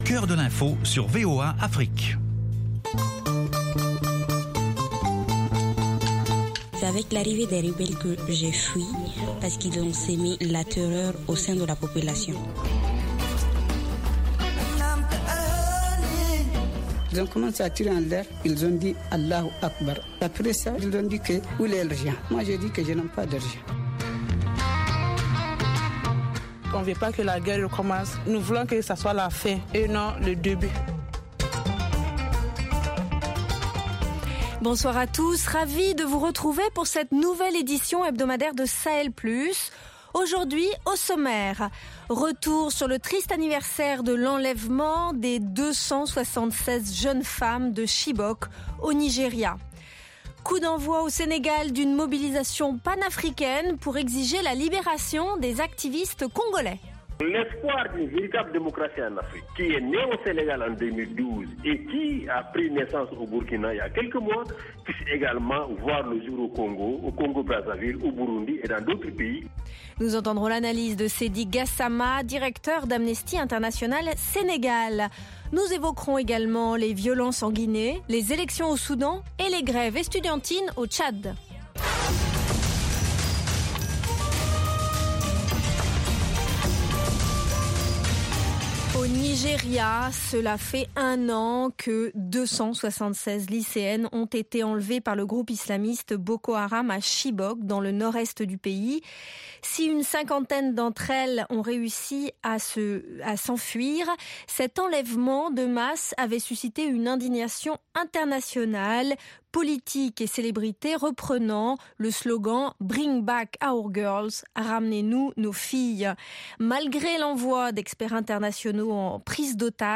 cette émission quotidienne est consacrée à l’actualité et aux challenges du Sahel avec des analyses, des dossiers thématiques et une page culture.